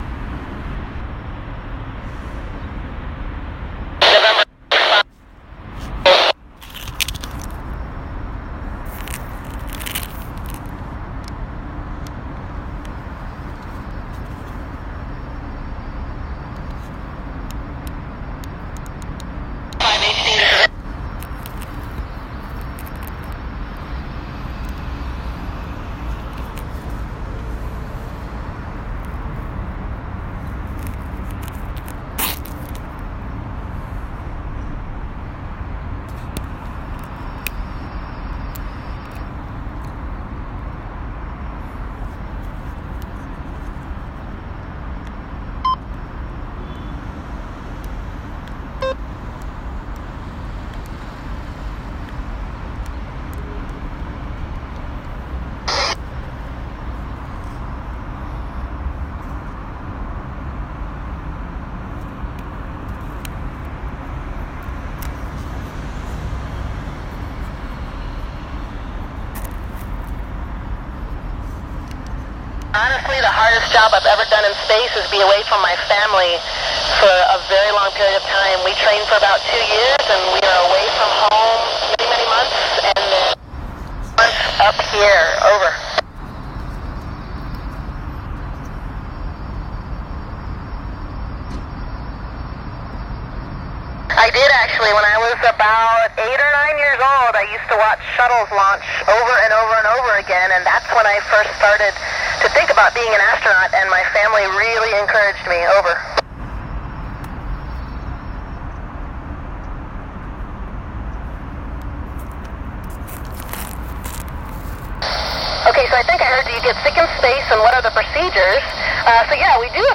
NA1SS School Q/A using a Yaesu FT-50R with stock antenna
10/18/18 NA1SS - Albequerque High School Q/A - Astronaut Audio only